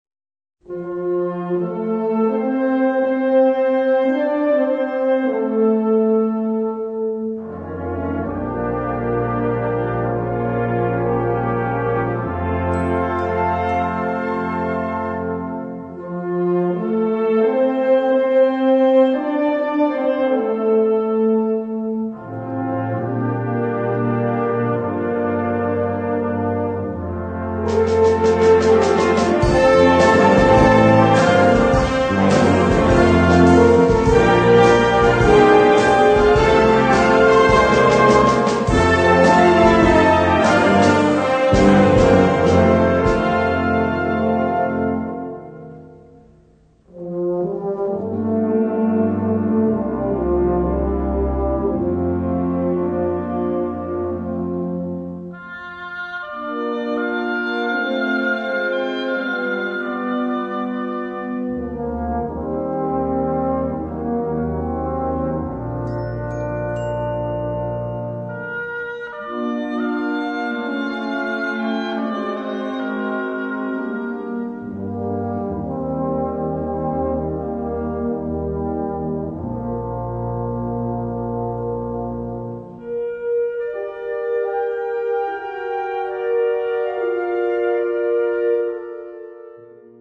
Besetzung Ha (Blasorchester)
ist ein lebhafter und leicht swingender Negrospiritual